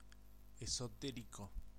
Ääntäminen
Vaihtoehtoiset kirjoitusmuodot esoterical (vanhentunut) esoterick Synonyymit recondite cerebral secretive obscure arcane Ääntäminen US UK : IPA : /ˌɛs.əʊ.ˈtɛɹ.ɪk/ US : IPA : /ˌɛs.ə.ˈtɛɹ.ɪk/ IPA : /ˌɛs.oʊ.ˈtɛɹ.ɪk/